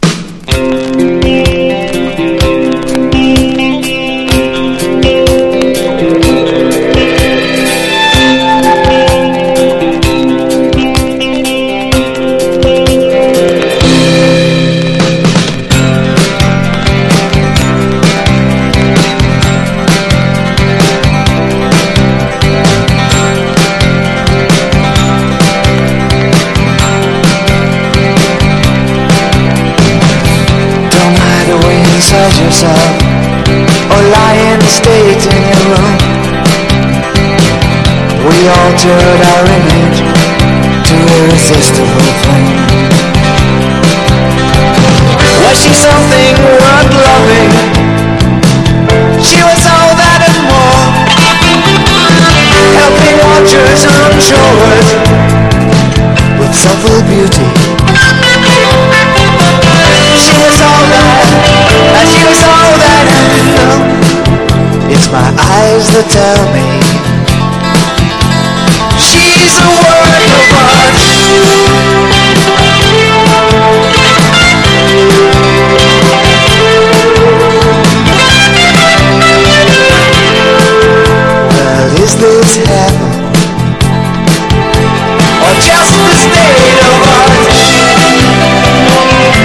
NEO ACOUSTIC / GUITAR POP